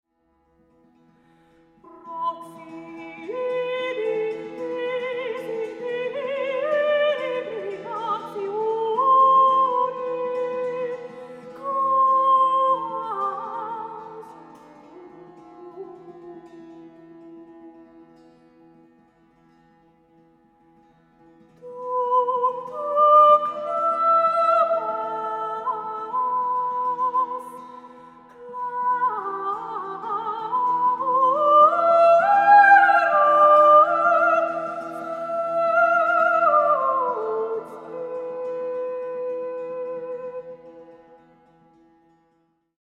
sopraano, dulcimer, sinfonia ja 5-kielinen kantele